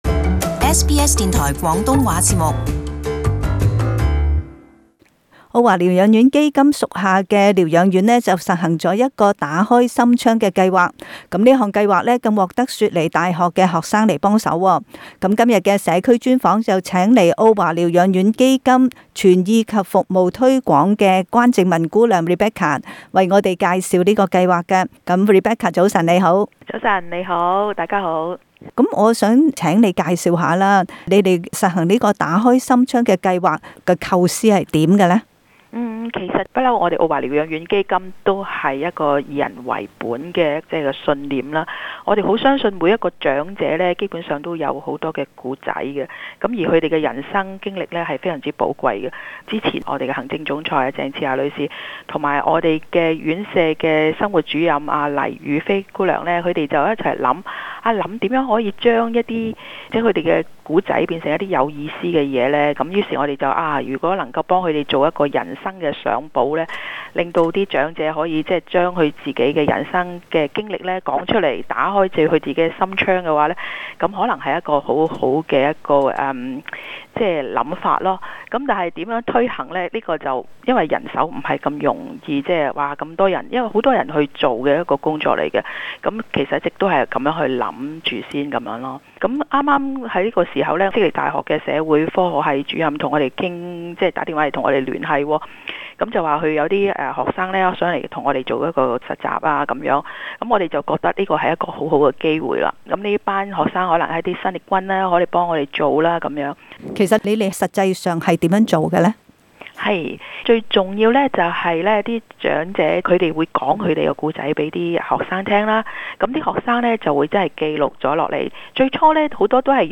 社區專訪